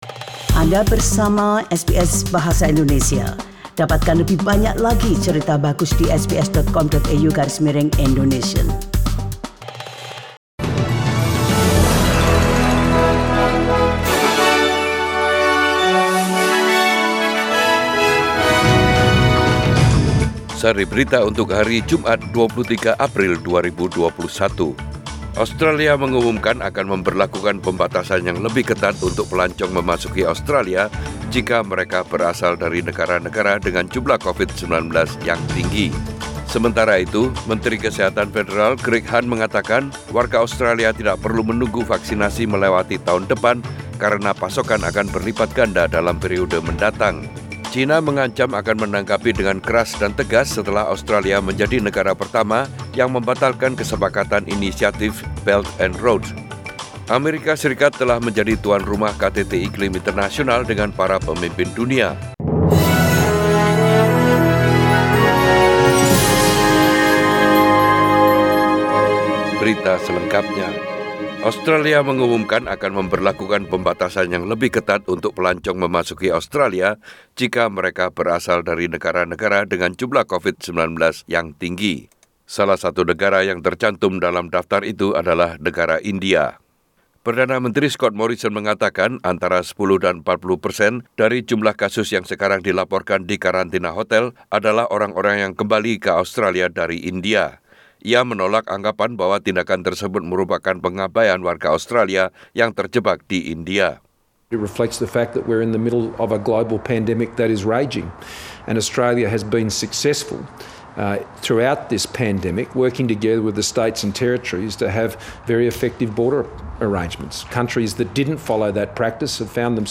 SBS Radio News Program in Bahasa indonesia - 23 April 2021